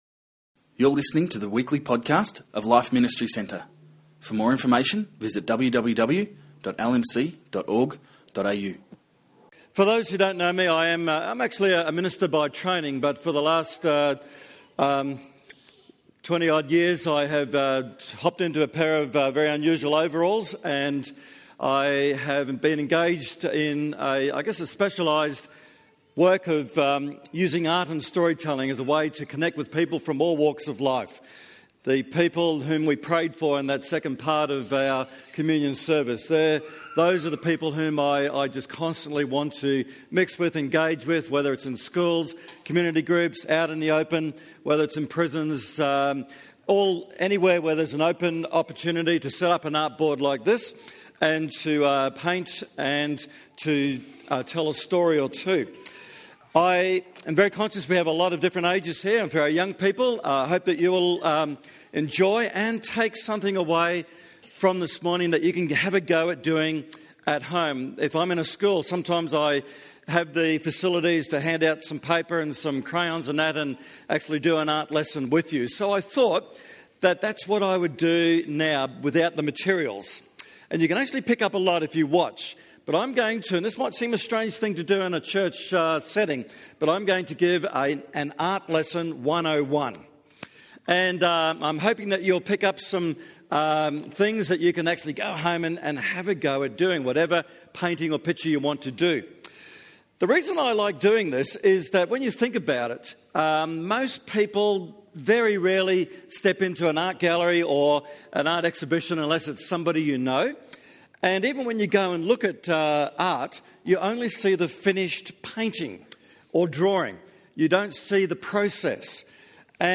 2014 Family Service